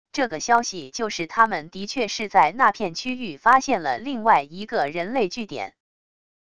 这个消息就是他们的确是在那片区域发现了另外一个人类据点wav音频生成系统WAV Audio Player